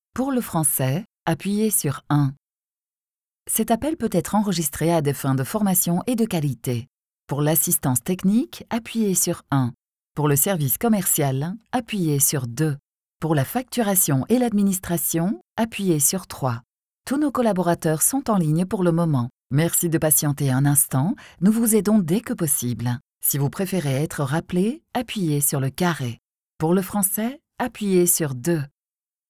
Warm, Diep, Vertrouwd, Volwassen, Zakelijk
Telefonie